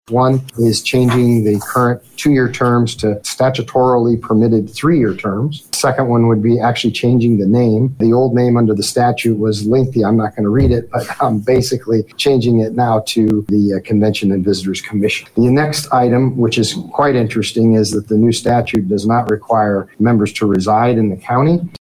During Monday’s meeting, County Attorney Kurt Bachman shared with the Commission that when the County Council approved the increase in the County’s Innkeepers Tax last week, it also allowed for updates to the Tax Commission, which hasn’t been changed since it was created in 1993.
Bachman presented the Commissioners with six items for their consideration, and shares the first three.